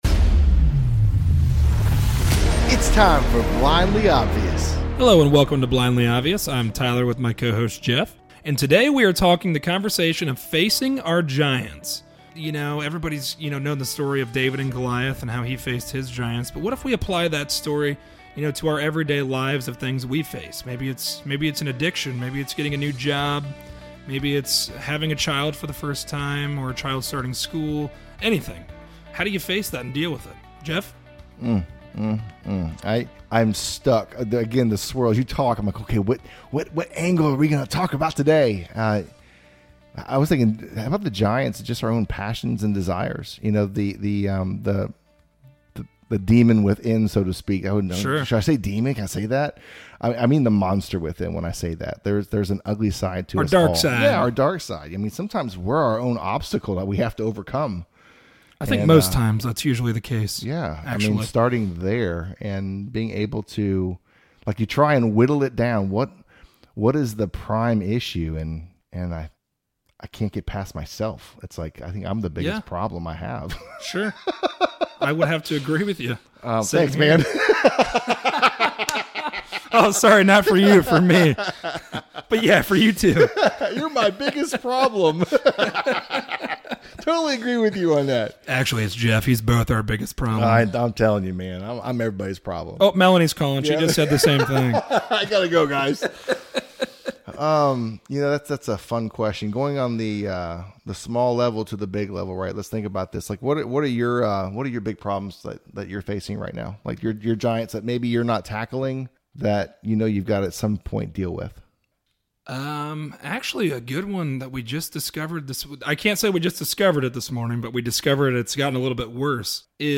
A conversation on facing our giants. Will we allow being a victim to hold us back or Faith to see us through?